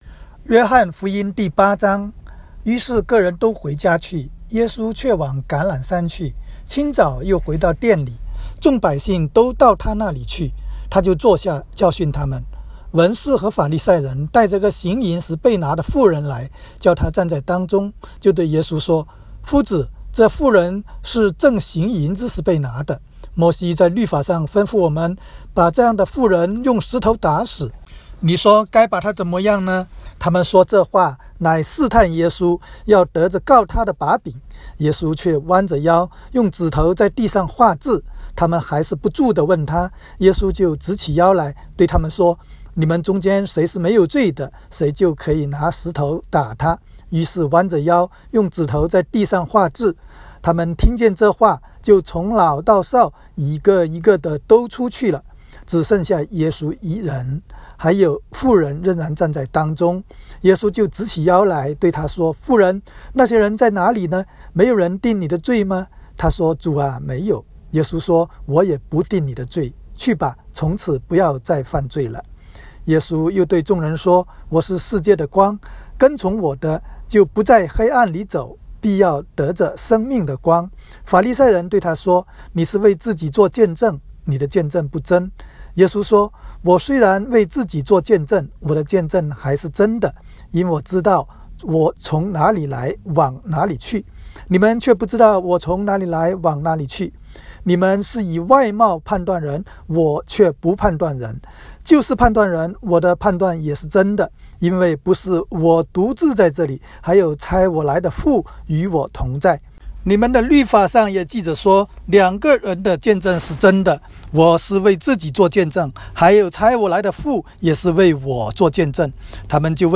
约08（经文-国）.m4a